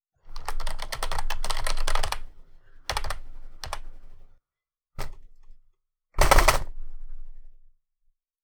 BLEEPINGCOMPUTER2.wav